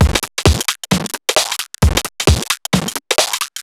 Index of /musicradar/uk-garage-samples/132bpm Lines n Loops/Beats
GA_BeatDCrush132-01.wav